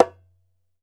ASHIKO 4 0NR.wav